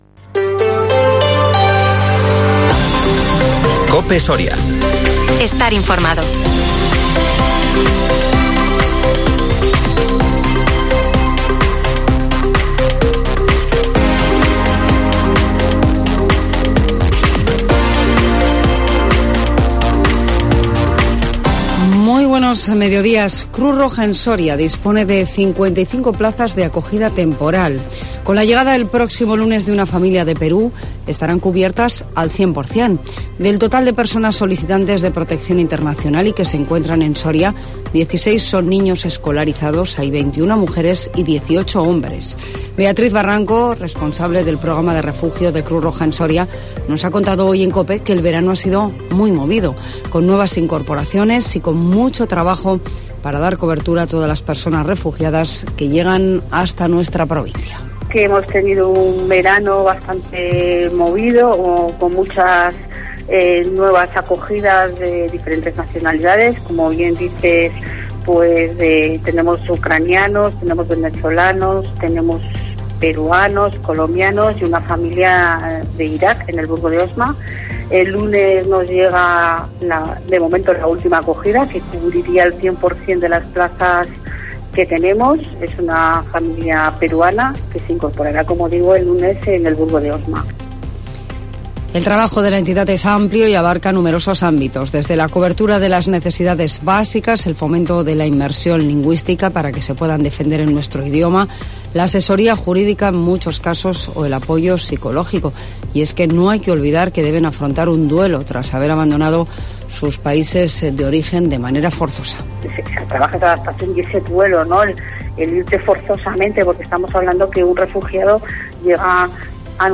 INFORMATIVO MEDIODÍA COPE SORIA MIÉRCOLES 18 OCTUBRE 2023